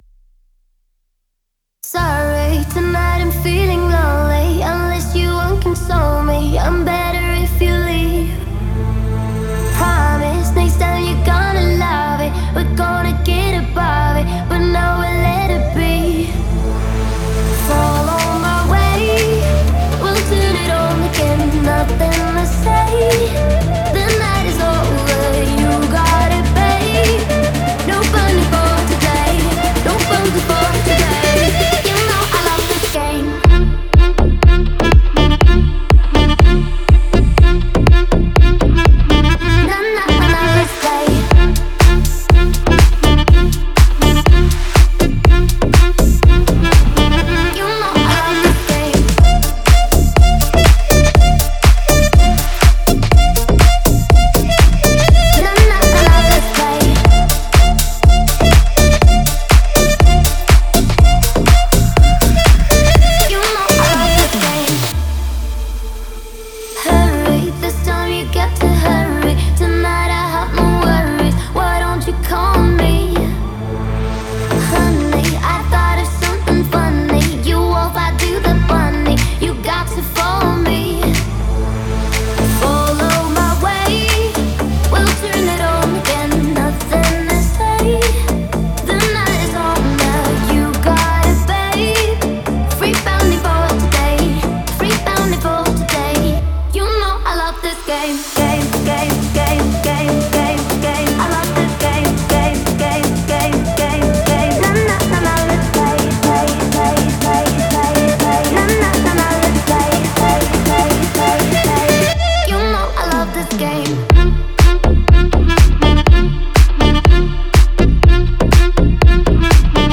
элементы электронной музыки и хип-хопа